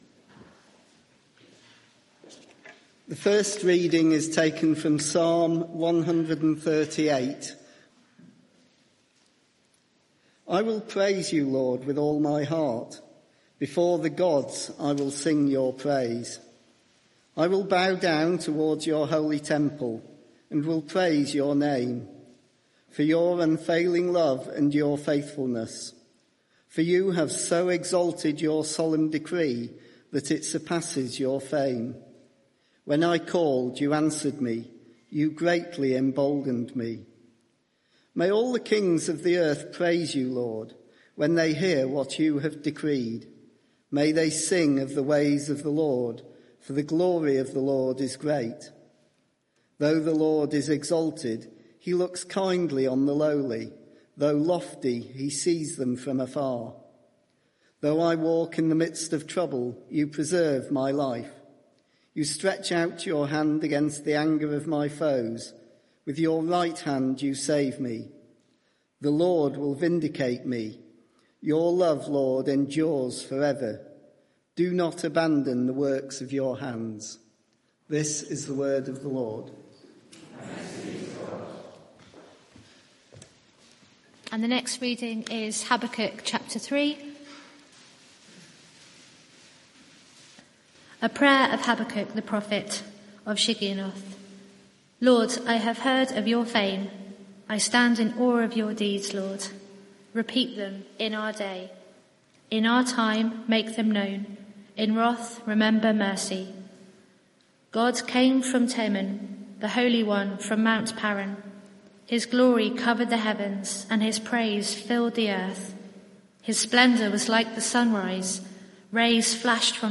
Media for 6:30pm Service on Sun 22nd Jun 2025 18:30 Speaker
Sermon (audio) Search the media library There are recordings here going back several years.